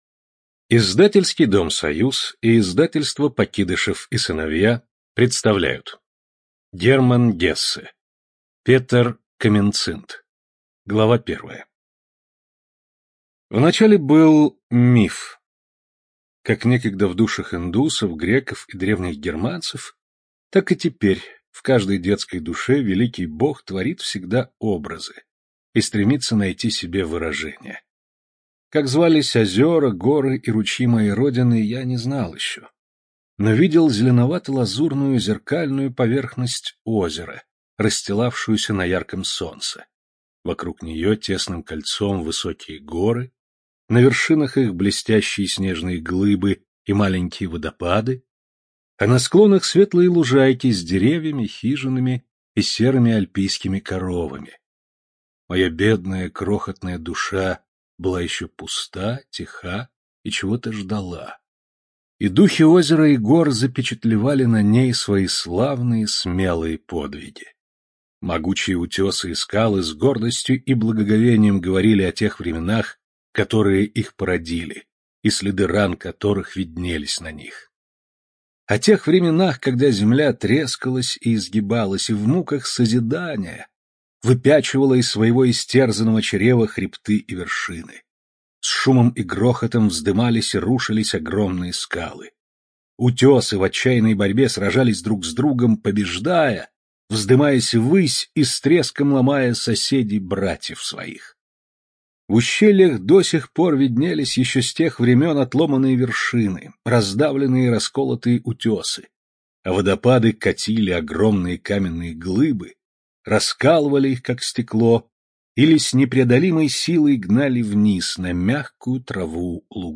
ЖанрКлассическая проза
Студия звукозаписиСоюз